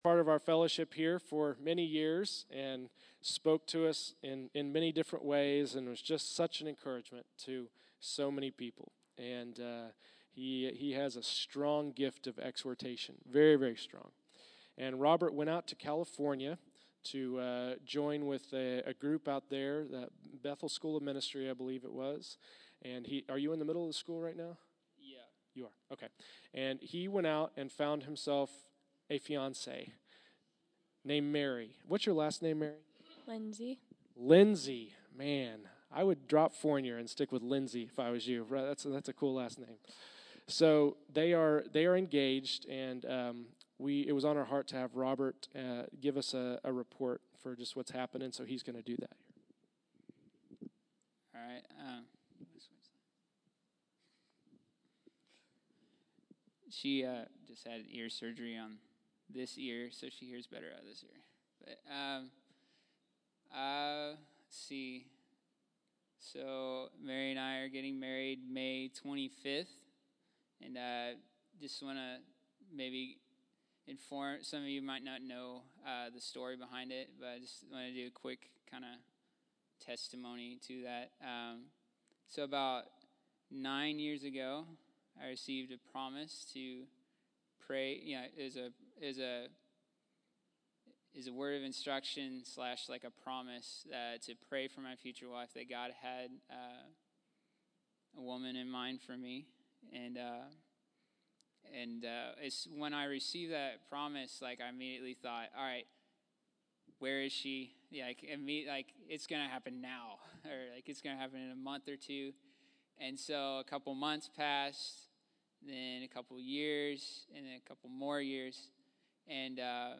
April 19, 2015      Category: Testimonies      |      Location: El Dorado